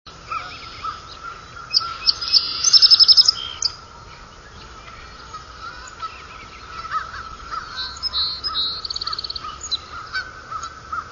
Several variations from the same bird "neighborhood", one large field, Collegeville, PA, 3/15/03:
Bluebirds and Geese can be heard in the background.
song_sparrow_705.wav